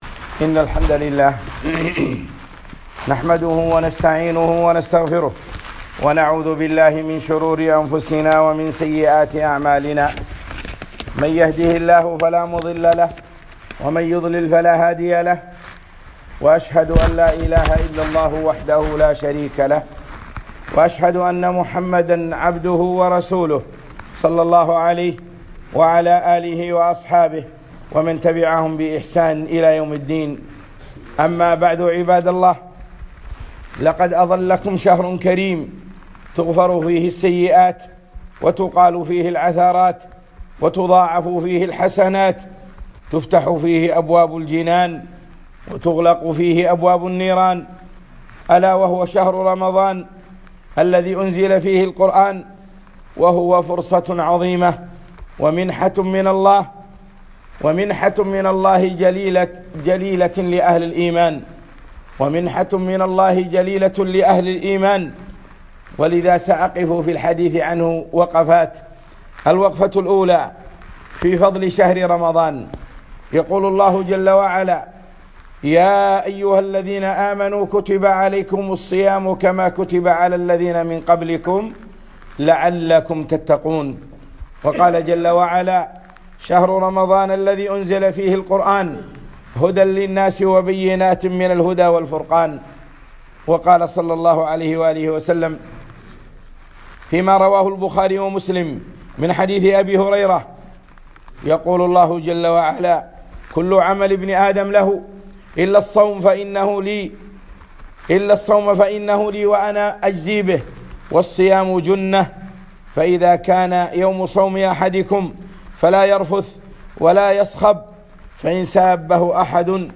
خطبة - شهر رمضان